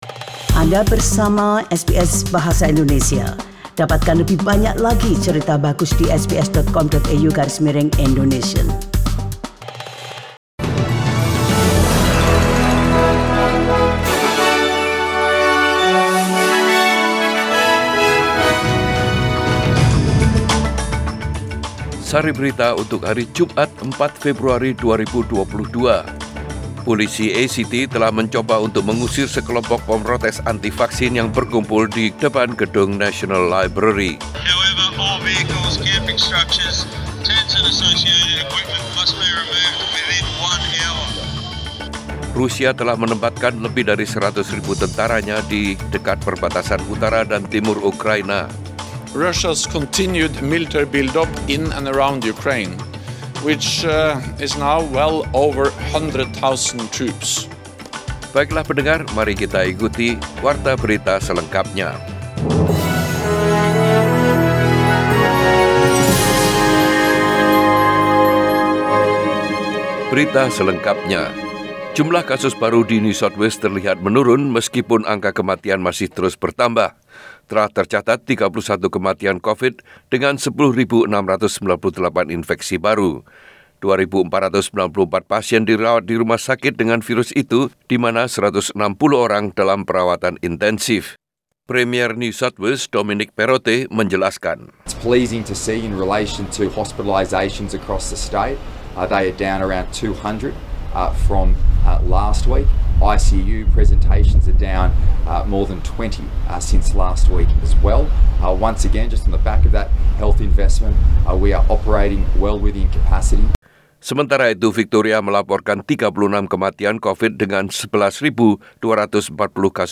SBS Radio News in Bahasa Indonesia - 4 February 2022
Warta Berita Radio SBS Program Bahasa Indonesia.